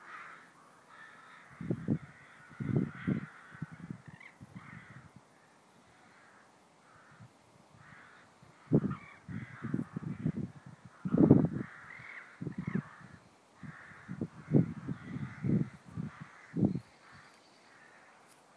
The crows heading home
96830-the-crows-heading-home.mp3